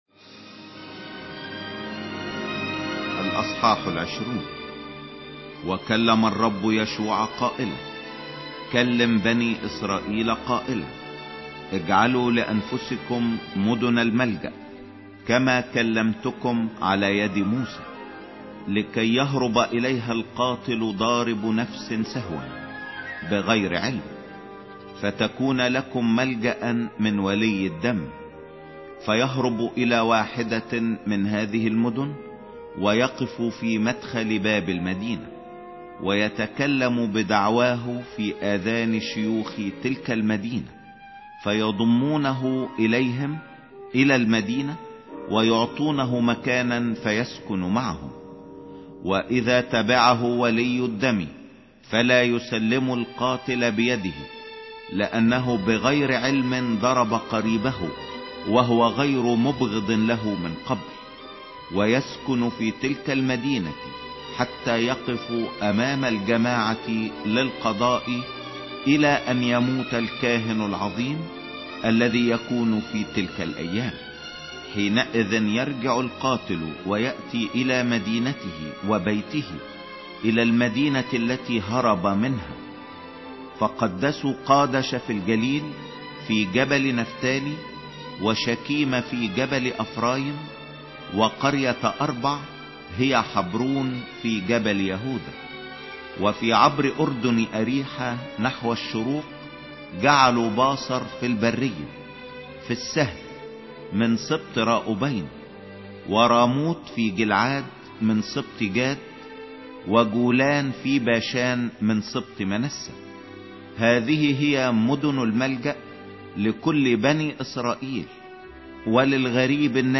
سفر يشوع 20 مسموع